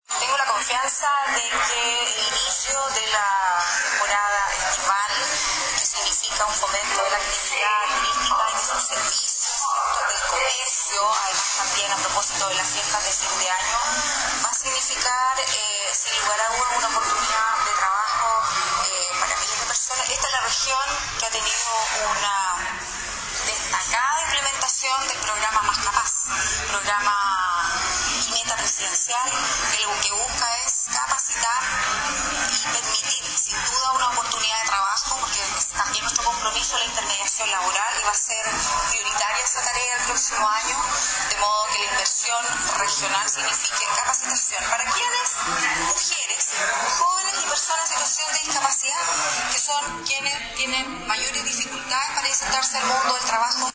(Advertencia: Audio con mucho ruido ambiente)